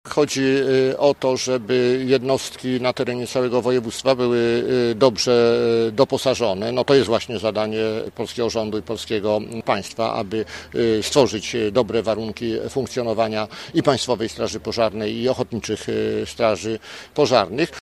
Zależy nam na dobrym doposażeniu lubuskich jednostek – mówi poseł Marek Ast: